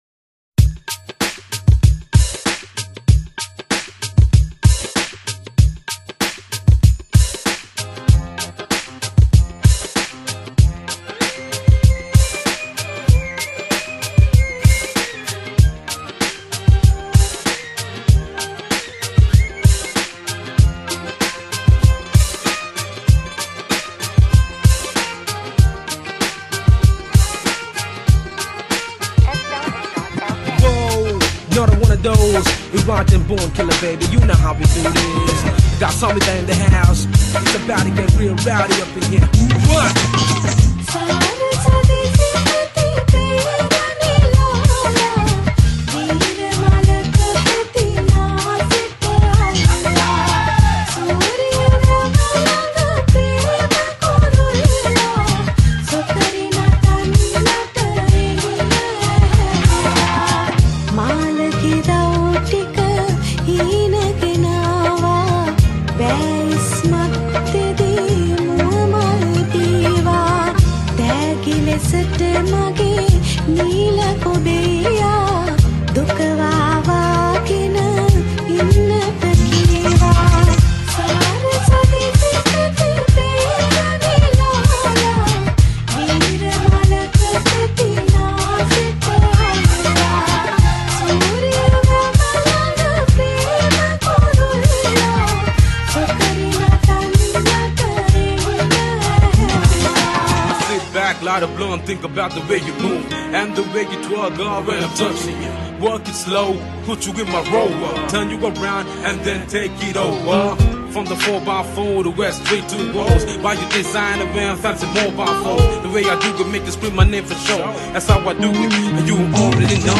High quality Sri Lankan remix MP3 (53.8).